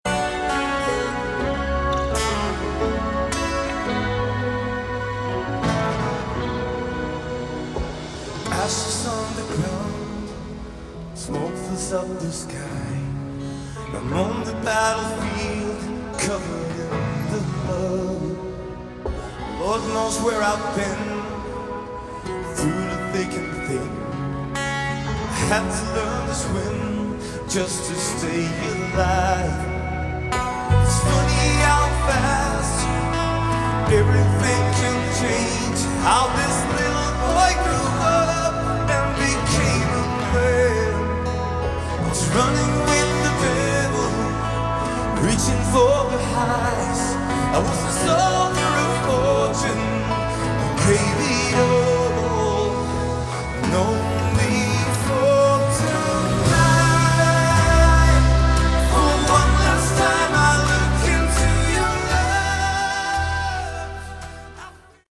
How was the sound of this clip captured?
recorded at Vaasa, Elisa Stadium in Finland in summer 2022.